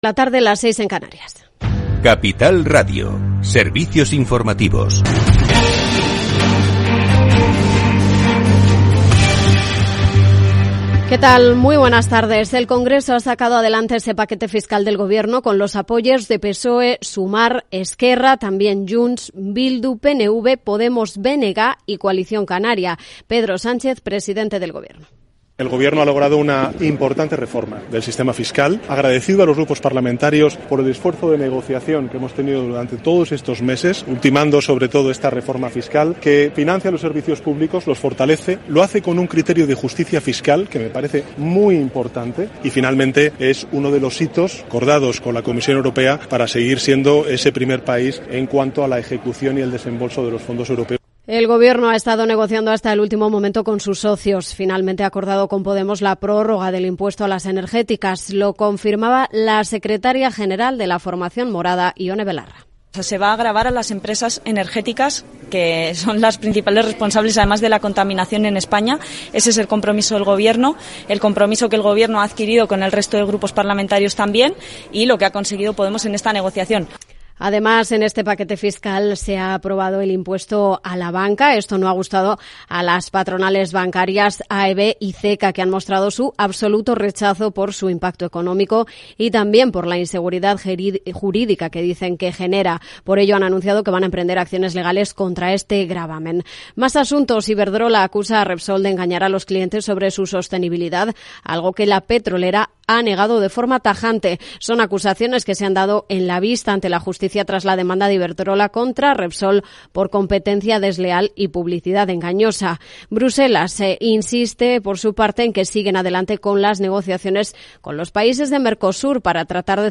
Programa diario dedicado a las pymes, profesionales, autónomos y emprendedores. Hablamos de Big Data y de crowdfunding, de management y coaching, de exportar e importar, de pedir créditos a los bancos y de empresas fintech. Los especialistas comparten sus experiencias, sus casos de éxito y sus fracasos. Todo en un tono propio de un Afterwork, en el que podrás hacer un poco de networking y hacer negocios más allá de la oficina.